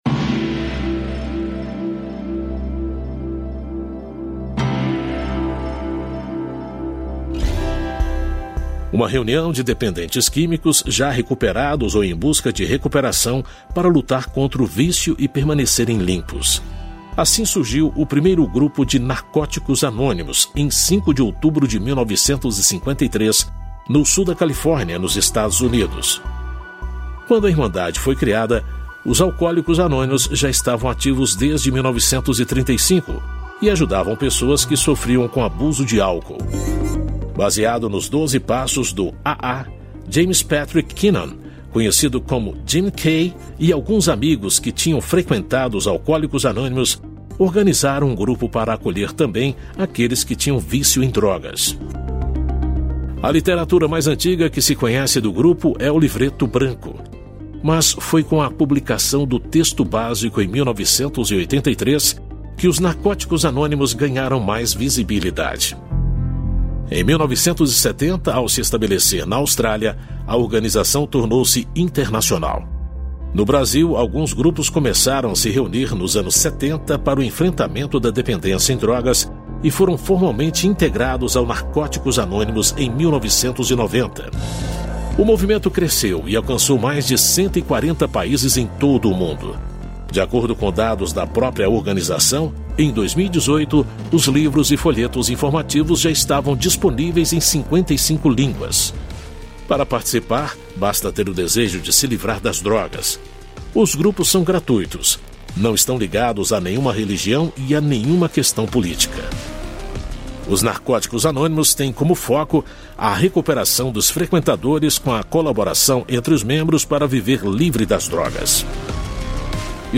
História Hoje é um quadro da Rádio Nacional publicado de segunda a sexta-feira na Radioagência Nacional.